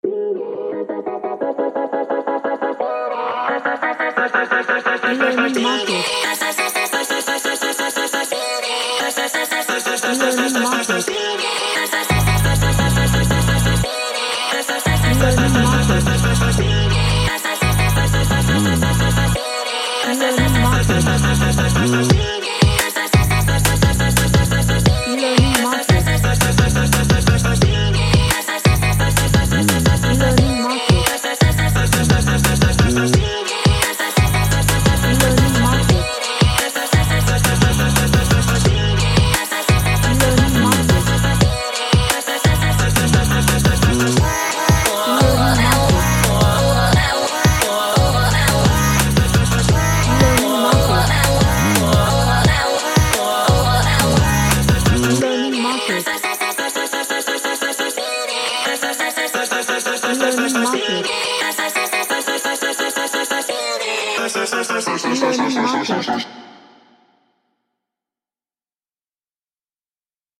A pop track with futuristic vibe
Sci-Fi / Future